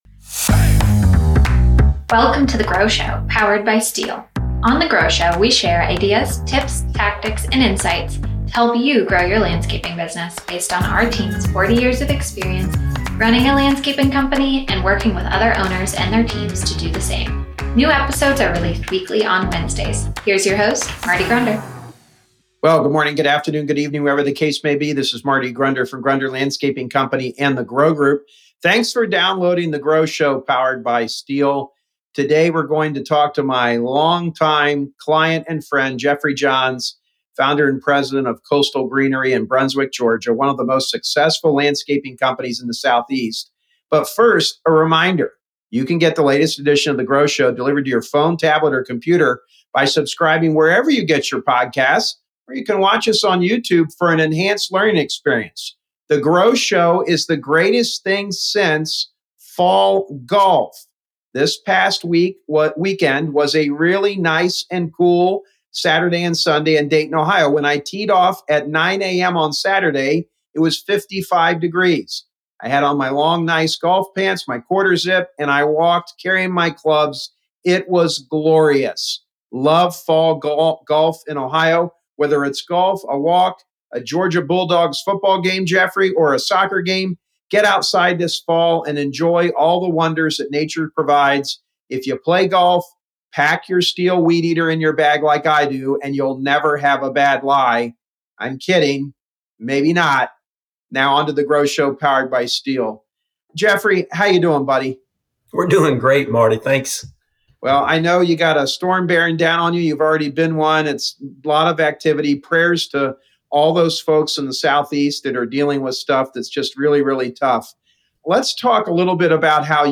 Interview Series